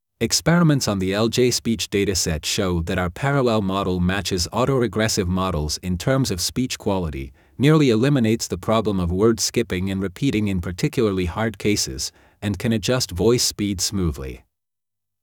neutral_ingles.wav